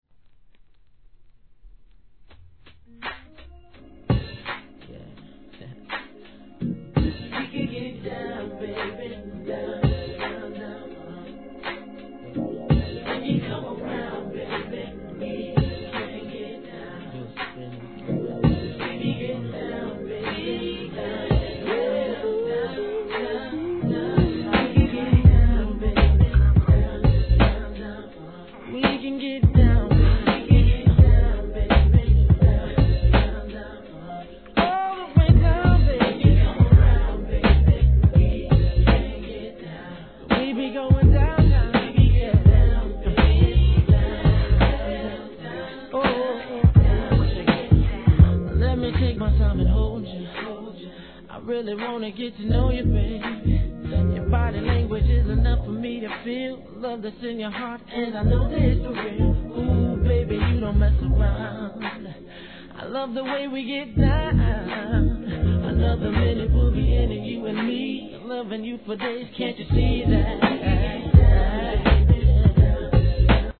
HIP HOP/R&B
1997年のインディ−R&Bですが、ミディアムでお洒落な雰囲気をかもし出す歌いっぷりはR&Bファンならほっとけないはず!